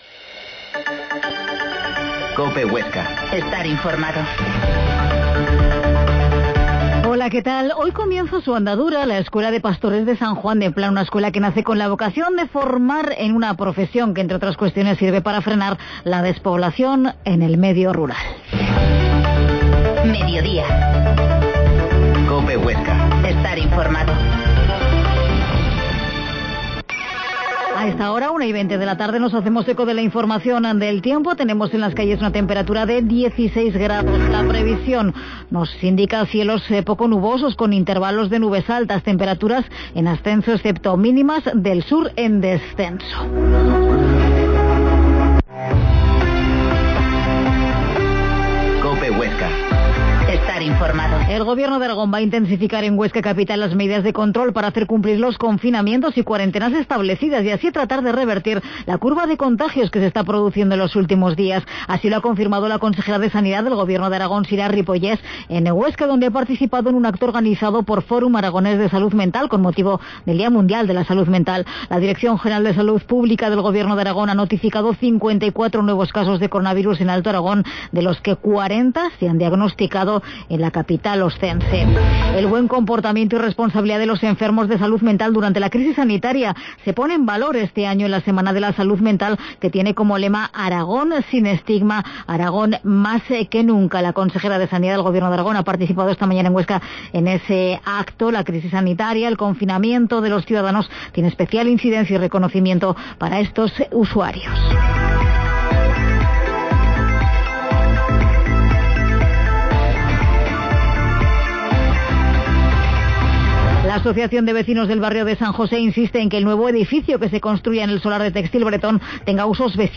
Mediodia en COPE Huesca 13.30h Reportaje sobre la escuela de pastores